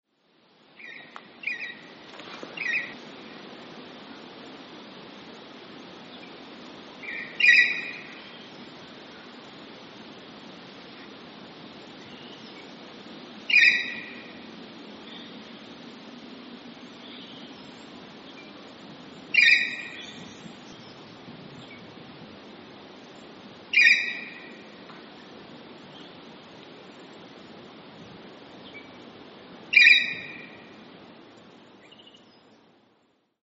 Grey Currawong - Strepera versicolor
Voice: loud ringing call.
Call 1: ringing call
Grey_Currawong.mp3